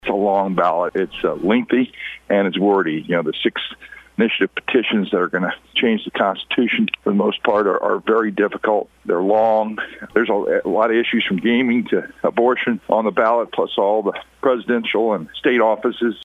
St. Francois County Clerk Kevin Engler noted the comprehensive nature of this year’s ballot, saying voters can expect several races and issues to decide on.